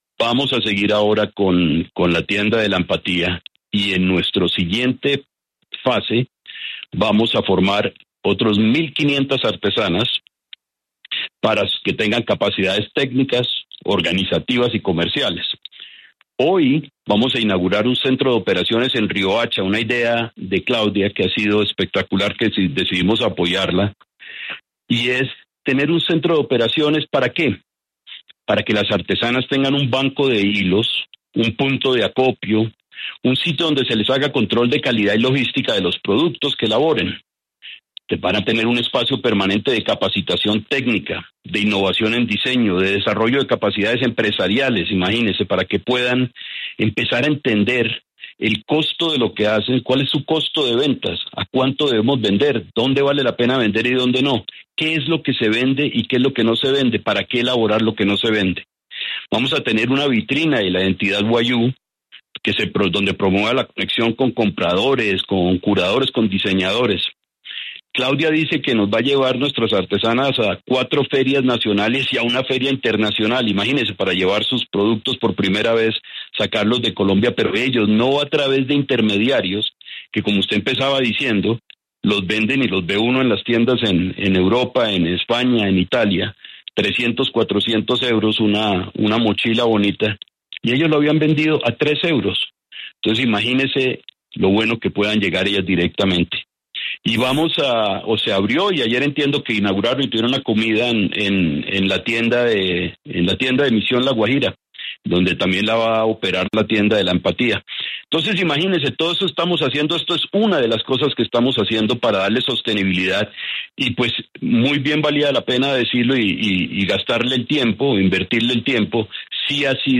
En conversación con La W, el presidente del Grupo Aval, Luis Carlos Sarmiento Gutiérrez reveló que se cumplió con la etapa inicial del proyecto Misión La Guajira, que ayudó a las comunidades indígenas de la región, pero aseguró que el trabajo aún no terminó.